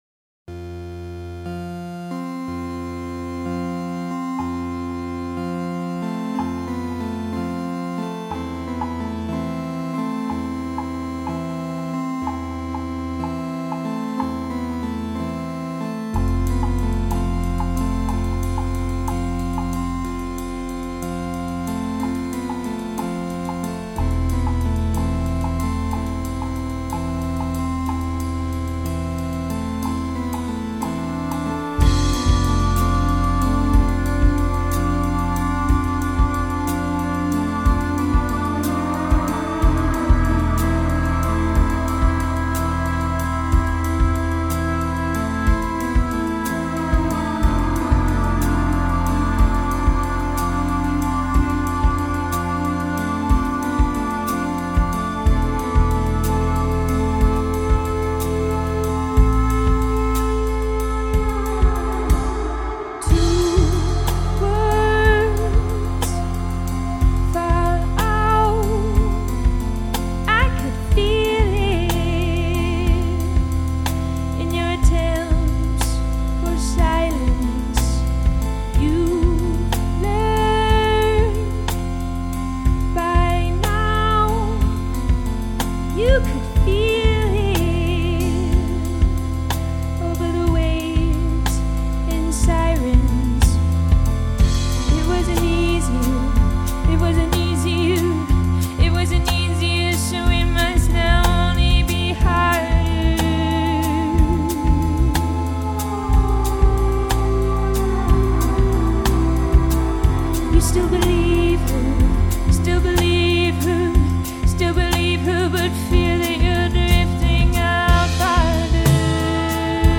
ethereal